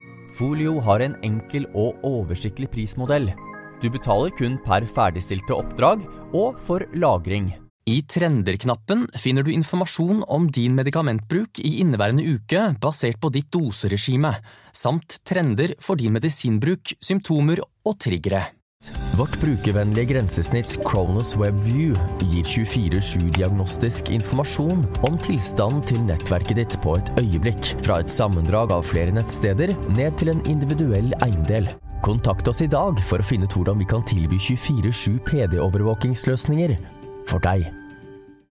Male
Corporate, Energetic, Friendly, Warm, Young
My home studio gear delivers great sound quality.
My voice sounds relatively young. It has a friendly, crisp and trustworthy touch.
Microphone: Neuman TLM 103